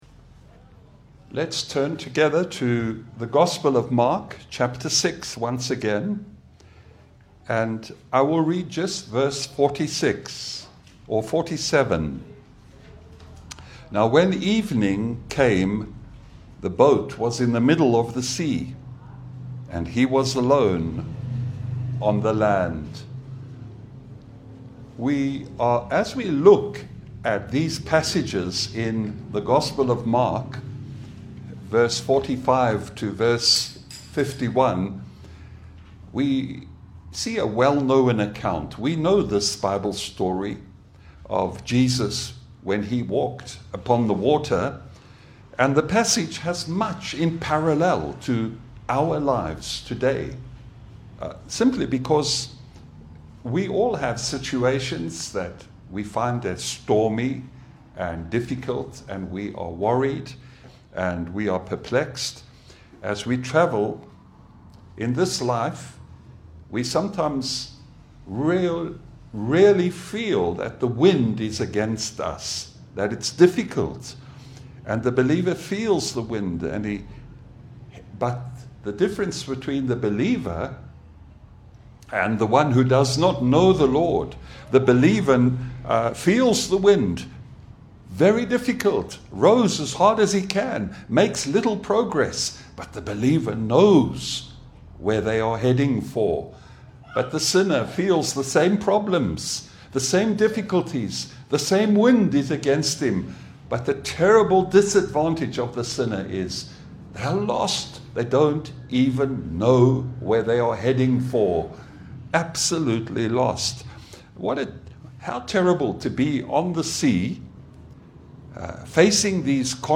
A Christ-centered sermon on Mark 6:45–51 showing Jesus as the Great I AM, sovereign over the storm, and the only source of true peace for troubled souls.
Mark 6:45 Service Type: Sunday Bible fellowship « Psalm 56 Sermon